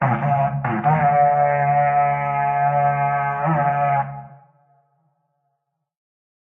Звуки рогов в Майнкрафт
Goat_Horn_Call2.mp3